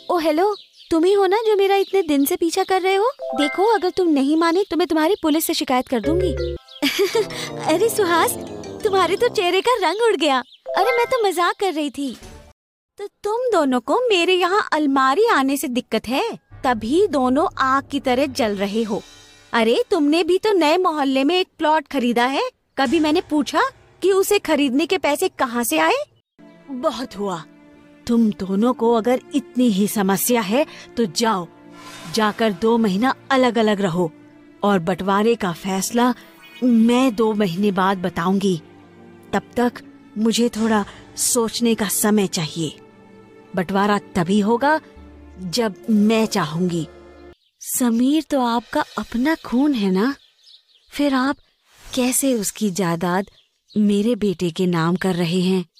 Jong, Natuurlijk, Veelzijdig, Vriendelijk, Warm